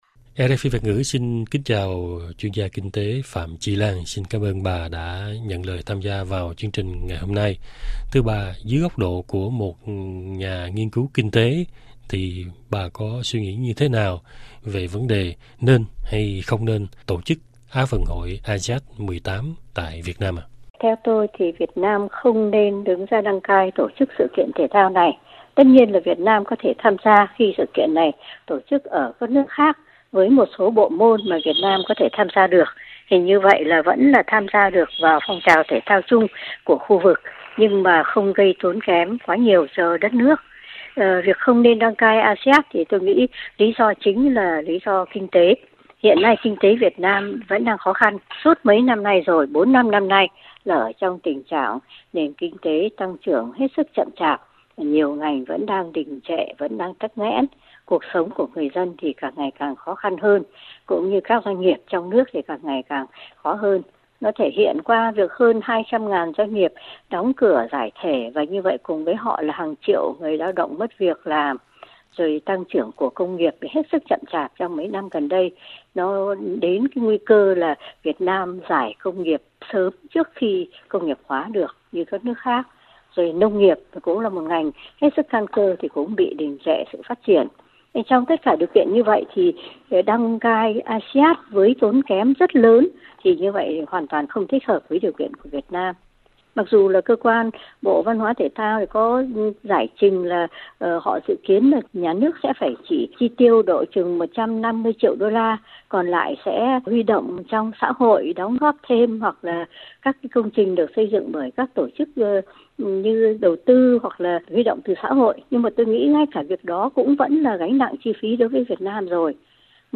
Chuyện đăng cai ASIAD với những khoản đầu tư khổng lồ từ ngân sách Nhà nước cũng một lần nữa làm nổi rõ sự thiếu minh bạch và thiếu hiệu quả của đầu tư công ở Việt Nam, như nhận định của chuyên gia kinh tế Phạm Chi Lan trả lời phỏng vấn RFI từ Hà Nội.
Chuyên gia kinh tế Phạm Chi Lan, Hà Nội